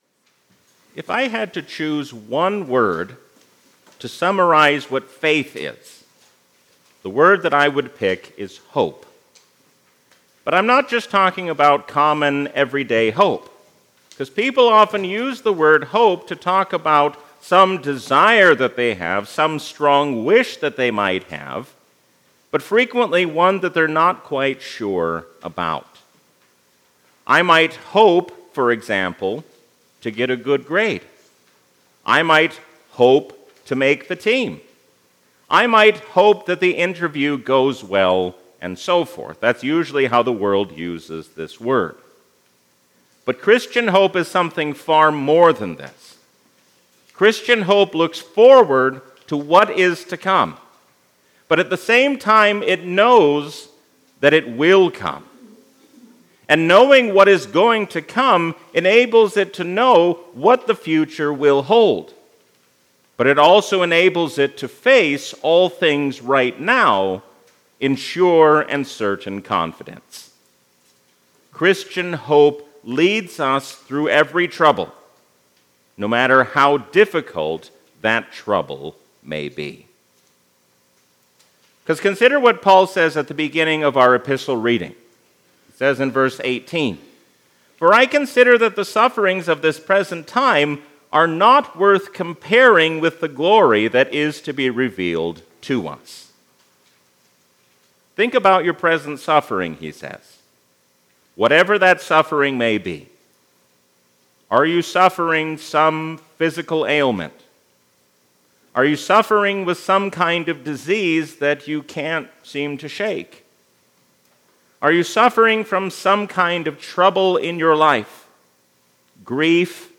A sermon from the season "Epiphany 2025." Since we will stand before the Lord, let us eagerly seek to do His will so that we will be ready to meet Him.